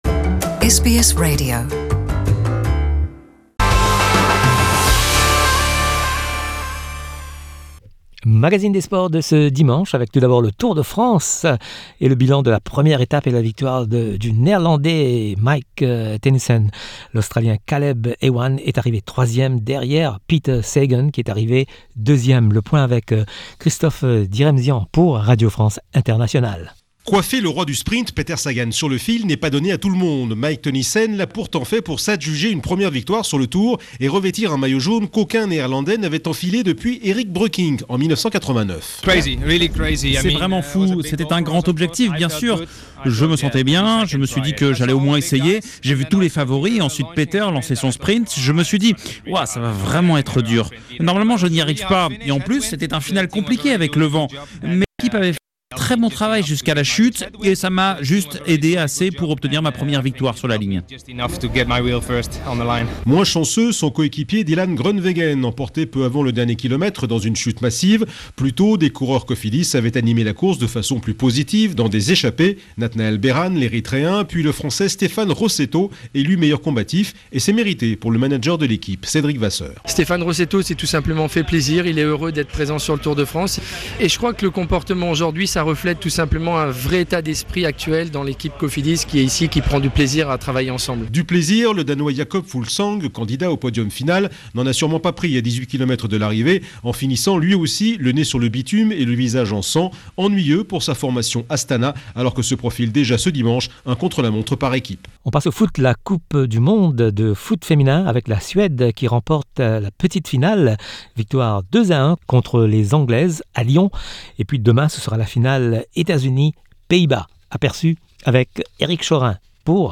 L’actualité sportive avec les sonores de RFI.